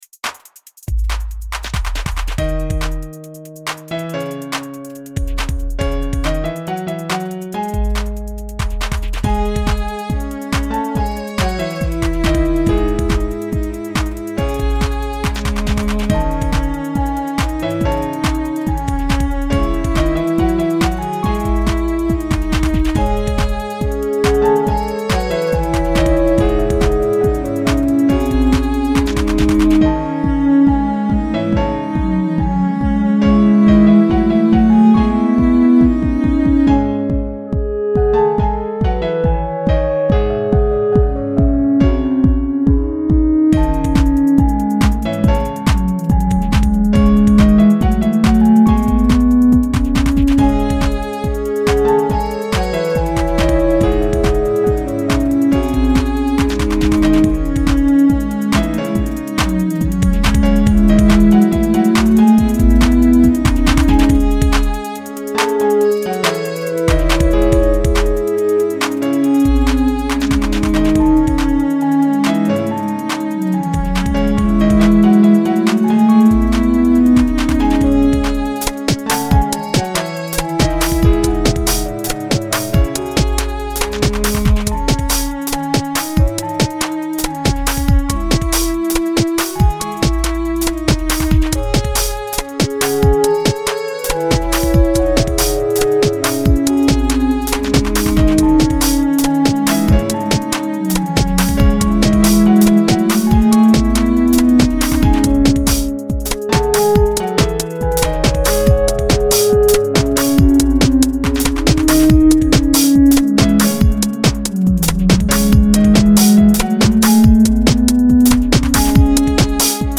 Posted in Classical, Dubstep Comments Off on